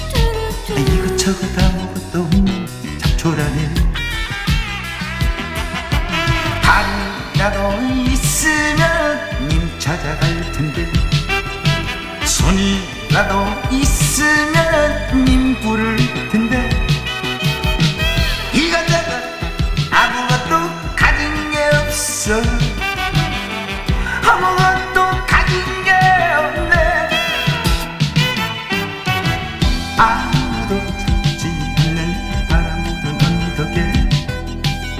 # Trot